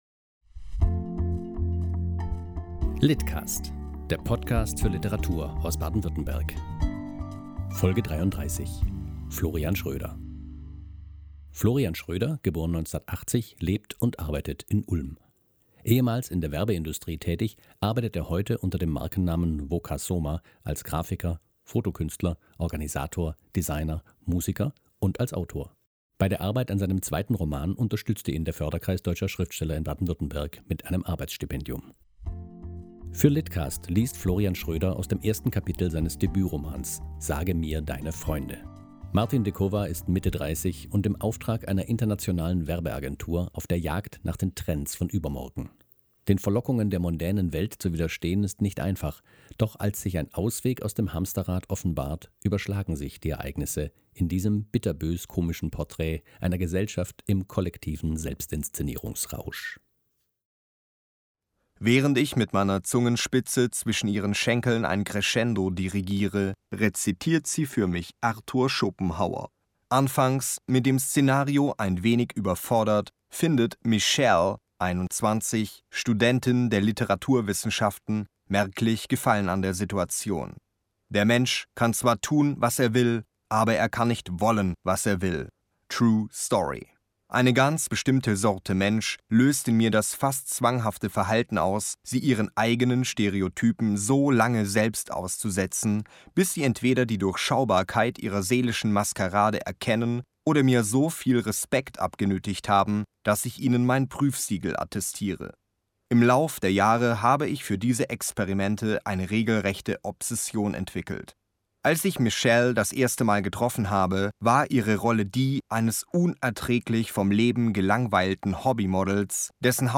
liest aus "Sage mir deine Freunde"